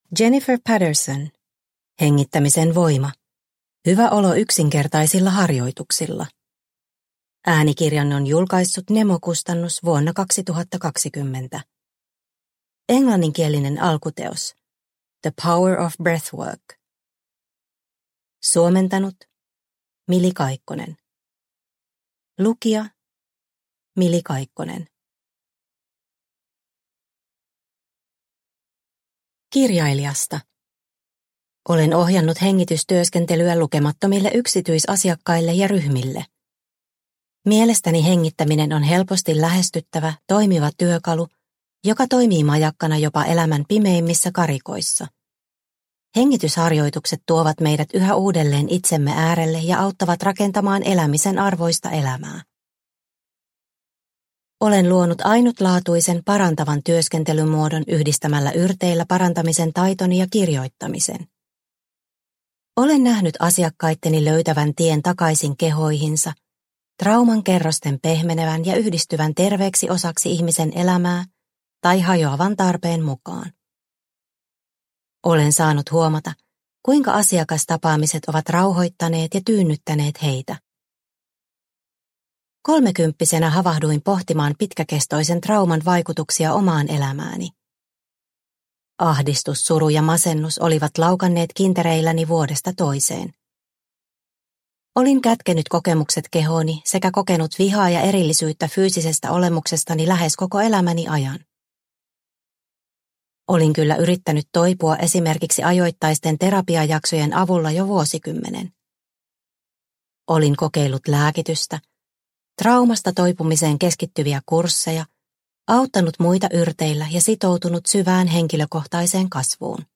Hengittämisen voima – Ljudbok – Laddas ner